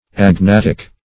Agnatic \Ag*nat"ic\, a. [Cf. F. agnatique.]